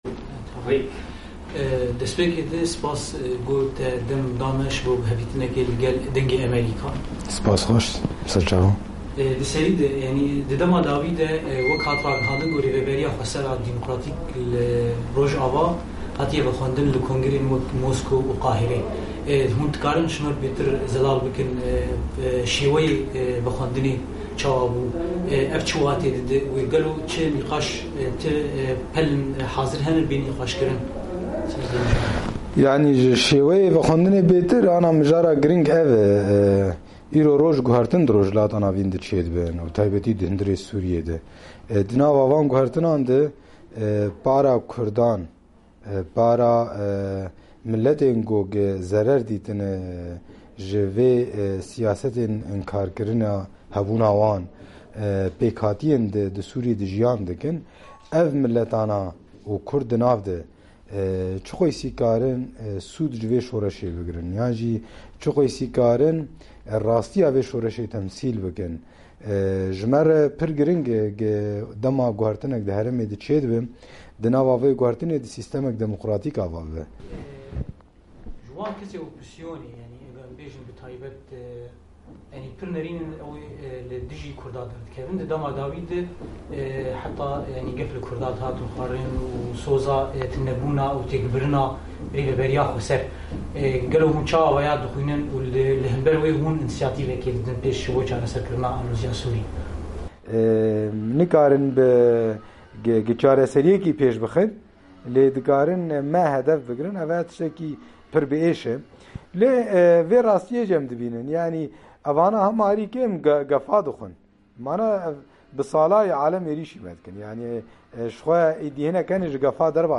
Hevpeyvîneke Taybet